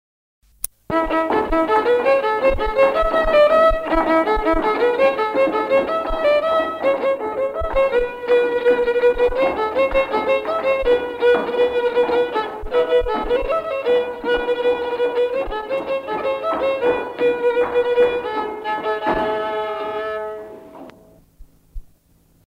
Instrumental. Violon.